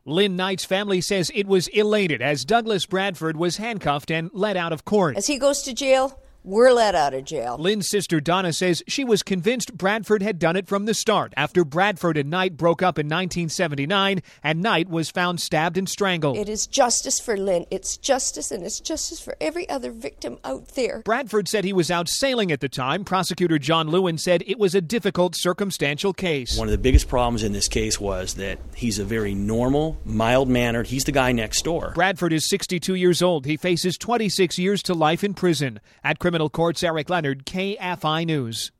spoke to reporters after the verdict was read